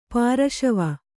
♪ pāraśava